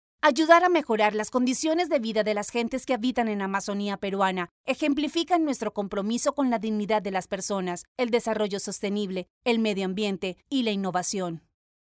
Latin American female voice overs